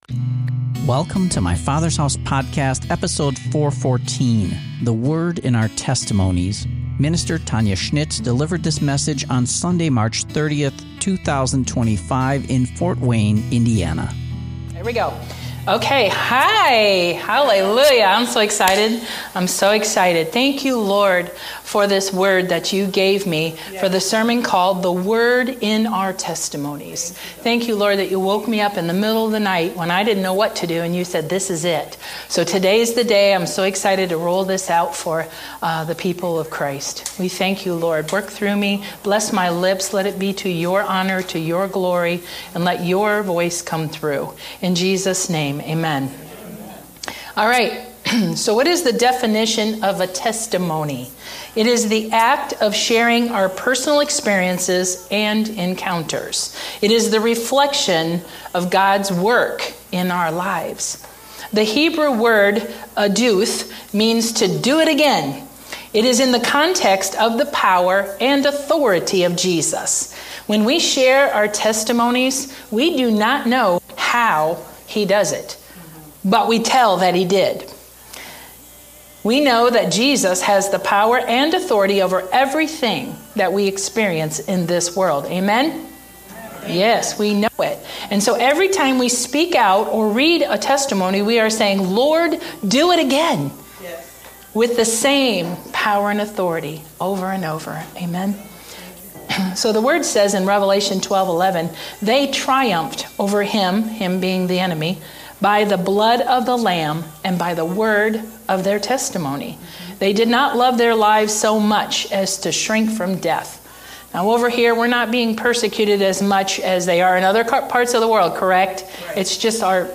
In this message many will be sharing of the Goodness of God and what He has done in their lives.